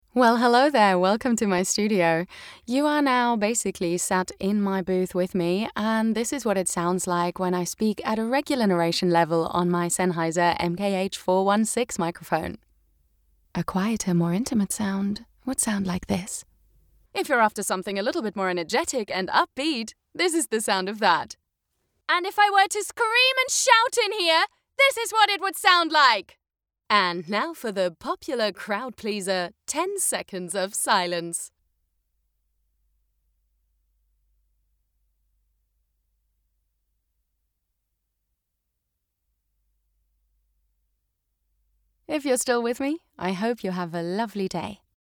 Female
Bright, Character, Cheeky, Children, Confident, Cool, Friendly, Natural, Soft, Versatile, Young, Engaging, Warm
British RP, Estuary, General American, Neutral English, Transatlantic, German (native), French, Russian, Scandinavian
Her voice is relatable, contemporary and youthful with a warm and textured sound.
Microphone: Sennheiser MKH 416, Rode NT1-A